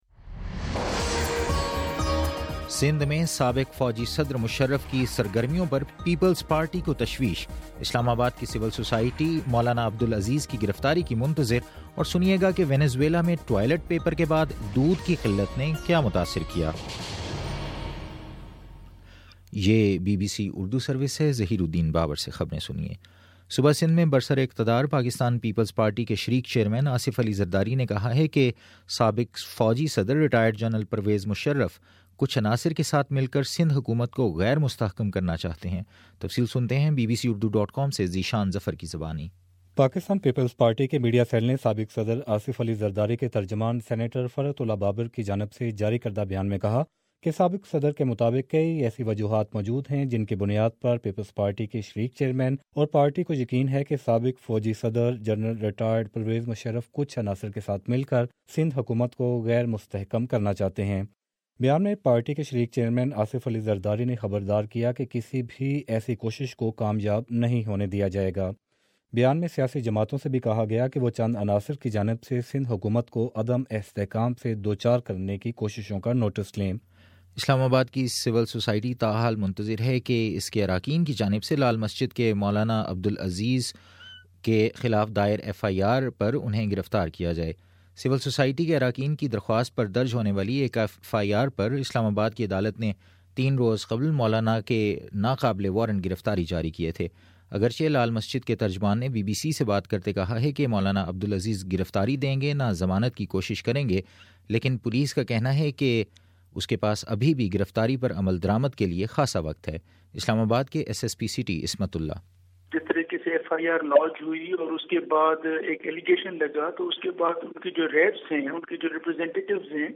دسمبر29 : صبح نو بجے کا نیوز بُلیٹن